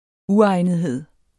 Udtale [ ˈuˌɑjˀnəðˌheðˀ ]